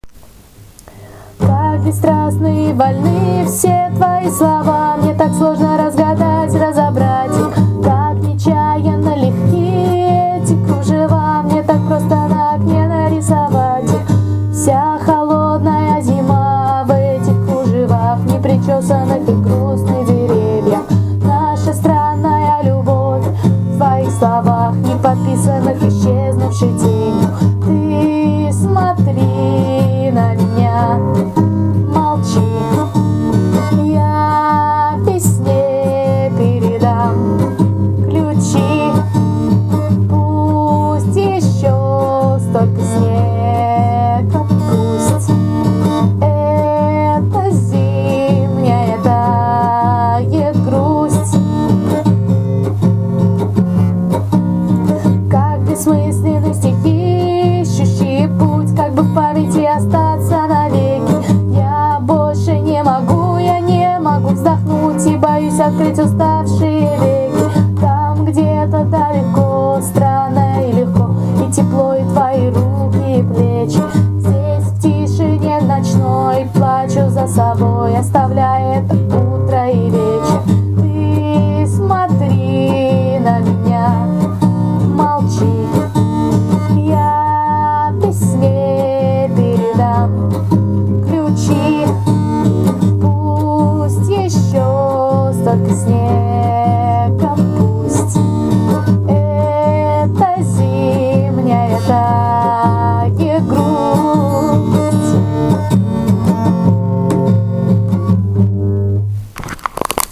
ïðîñòî òû íàïåâàåøü - êàê ðàç äëÿ ãèòàðêè âå÷åðîì õîðîøî.